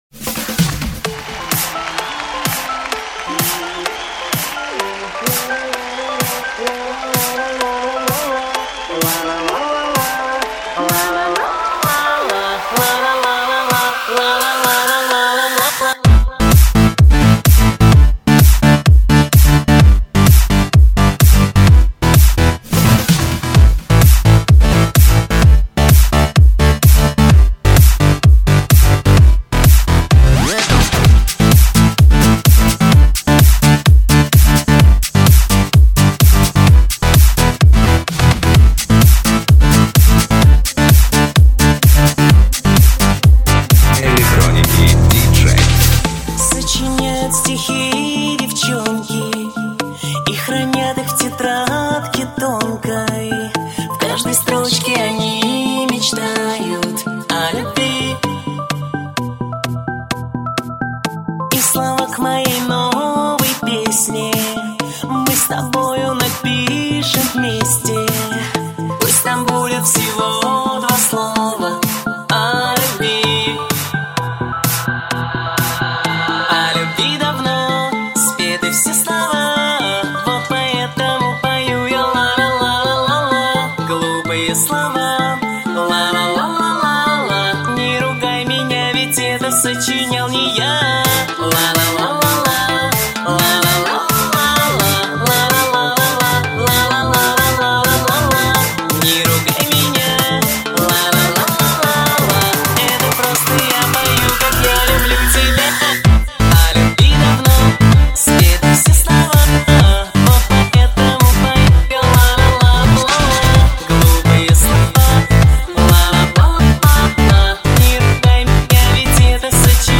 Стиль:Dance